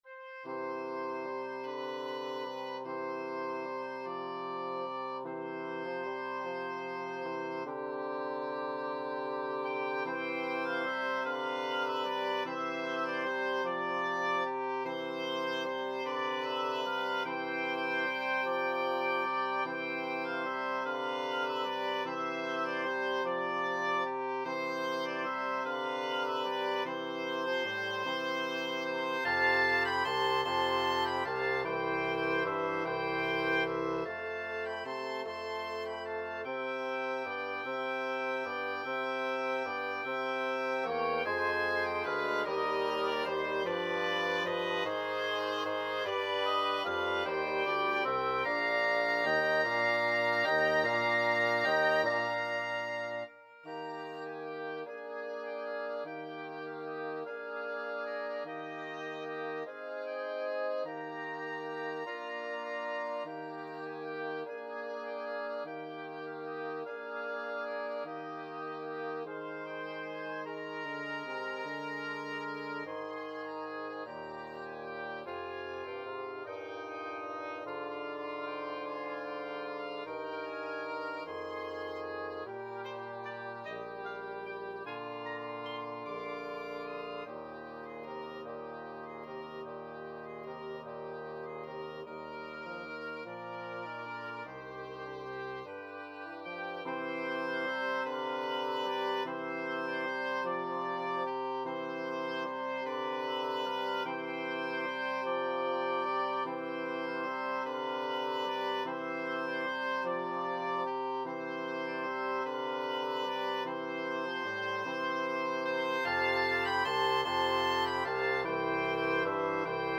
Wind Quartet version
Christmas
Oboe 1Oboe 2Bassoon 1Bassoon 2
Andante mosso . = c. 50
12/8 (View more 12/8 Music)
Classical (View more Classical Wind Quartet Music)